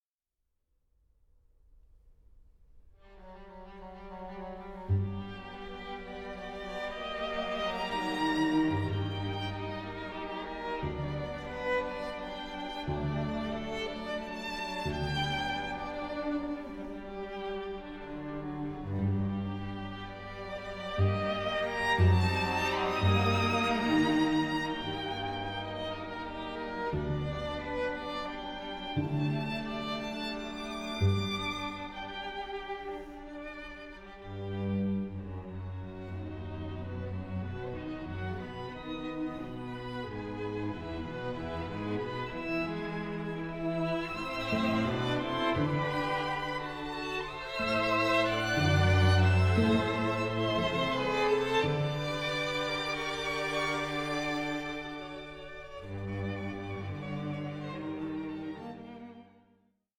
full, “orchestral” and rich in harmonies.
combining chamber-musical intimacy with “symphonic” depth.